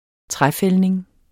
Udtale [ ˈtʁaˌfεlneŋ ]